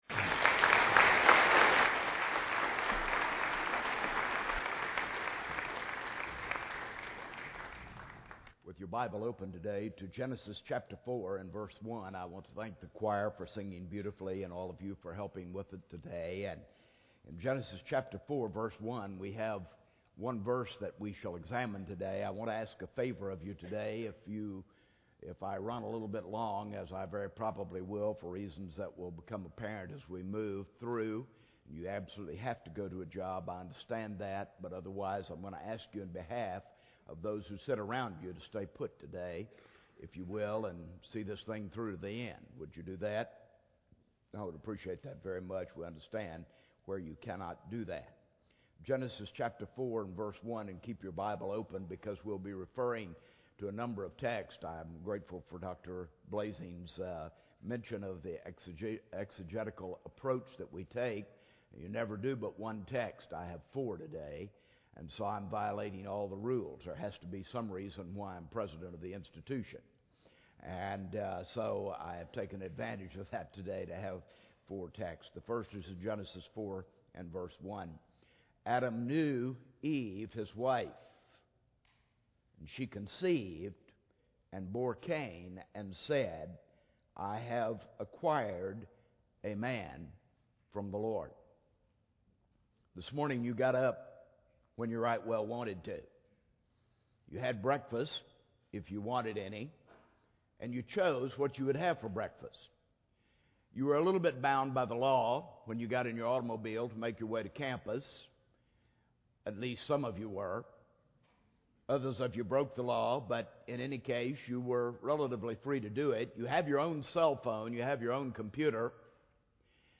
speaking on Song of Solomon 7:1-10; 1 Corinthians 7:1-5 in SWBTS Chapel on Wednesday February 25, 2009